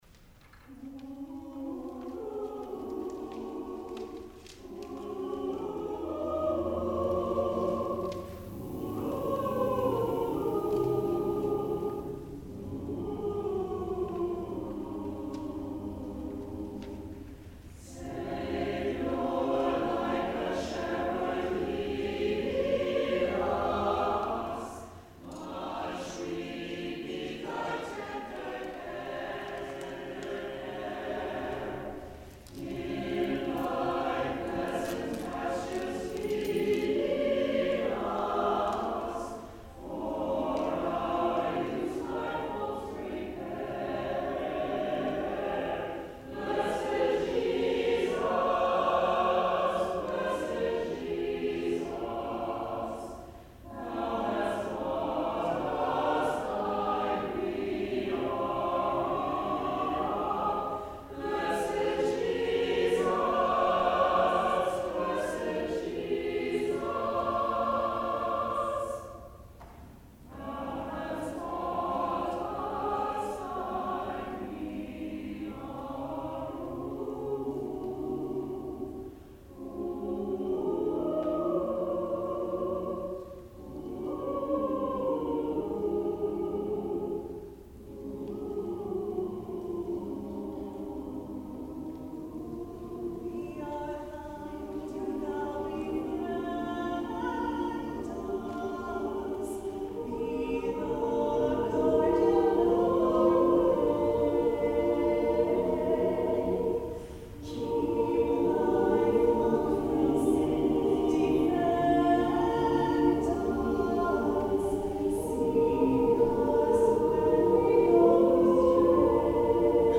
alto
organ
ANTHEM